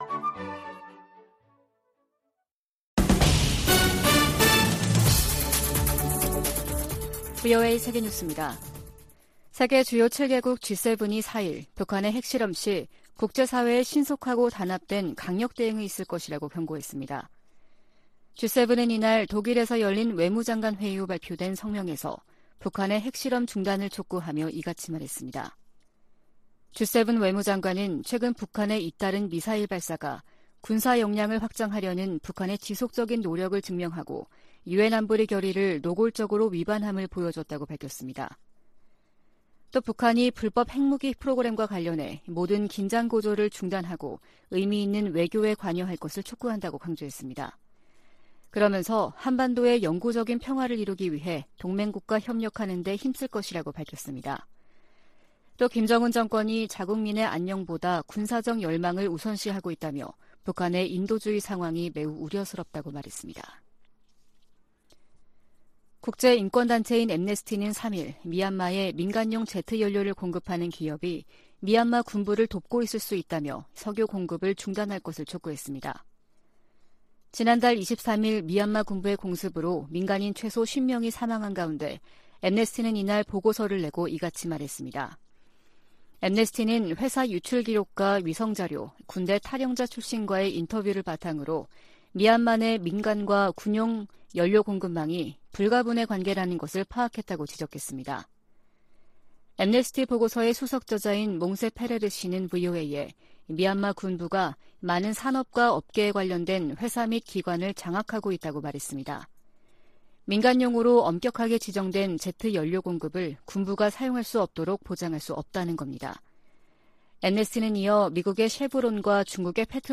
VOA 한국어 아침 뉴스 프로그램 '워싱턴 뉴스 광장' 2022년 11월 5일 방송입니다. 북한 김정은 정권이 오늘 대규모 군용기를 동원한 무력 시위를 하자 한국이 이에 대응해 스텔스 전투기 등 80여를 출격시키는 등 한반도에서 긴장이 계속되고 있습니다. 미국과 한국 국방장관이 미국 전략자산을 적시에 한반도 전개하는 방안을 강구하고 핵우산 훈련도 매년 실시하기로 합의했습니다.